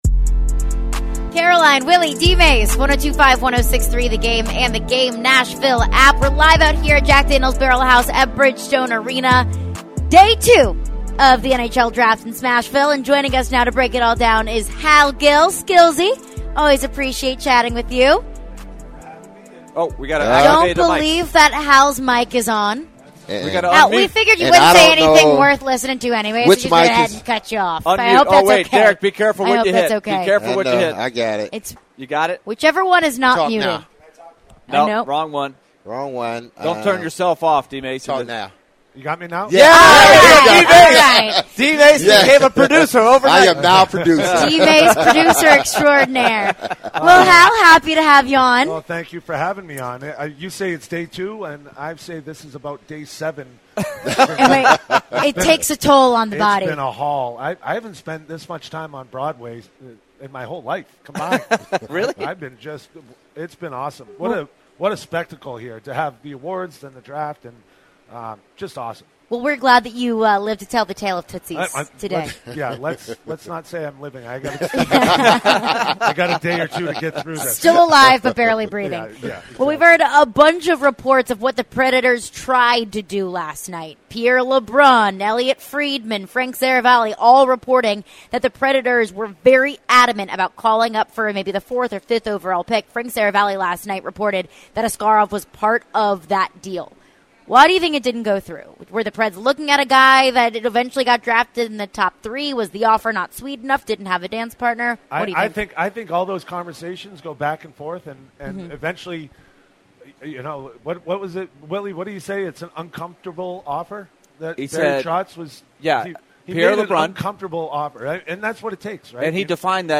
live from Bridgestone Arena